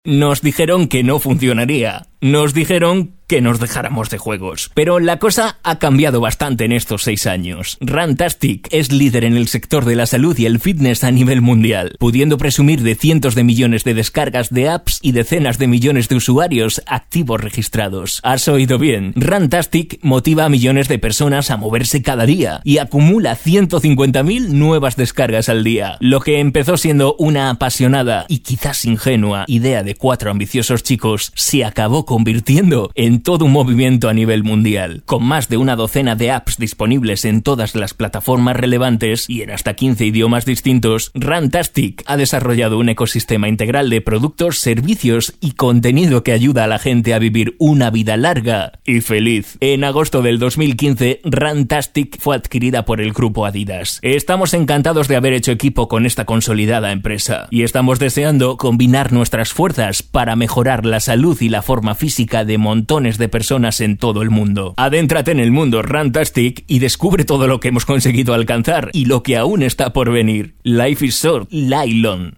kastilisch
Sprechprobe: eLearning (Muttersprache):